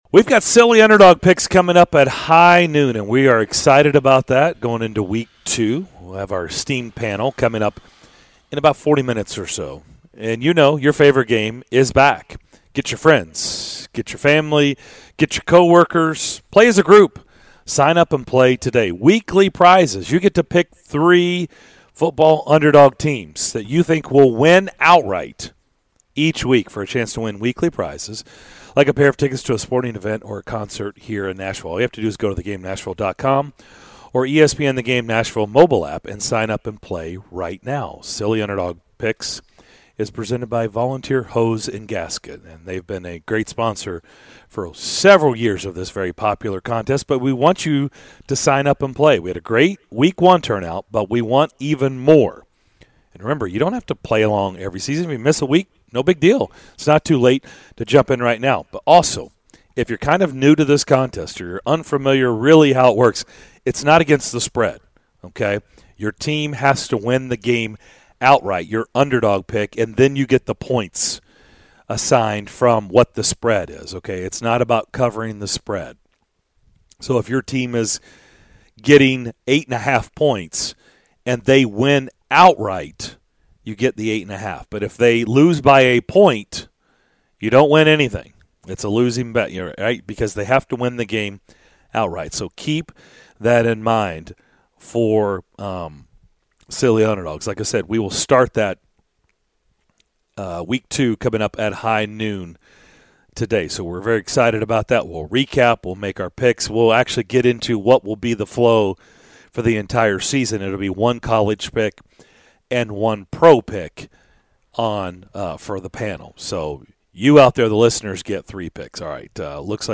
Tennessee Titans LB Rashaan Evans joined the DDC to discuss the dominance of his alma mater (Alabama), preparation for the 2021 season and more!